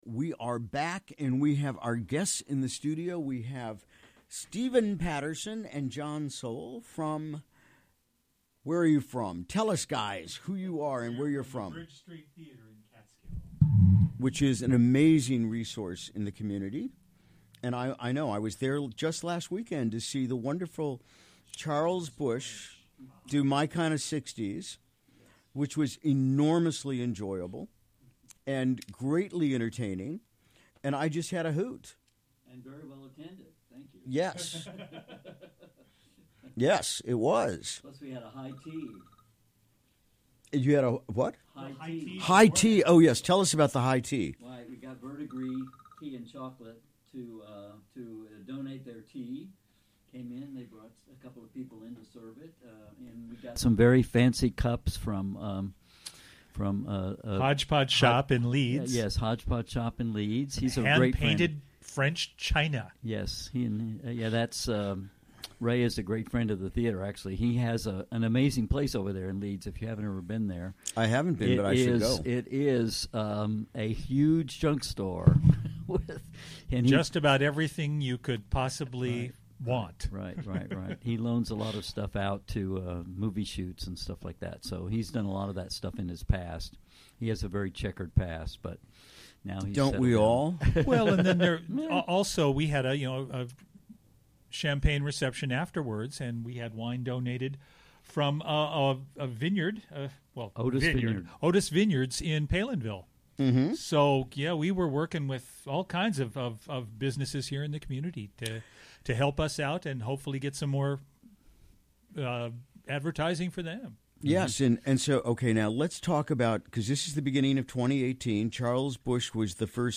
Recorded live during the WGXC Morning Show on Wednesday, February 28, 2018.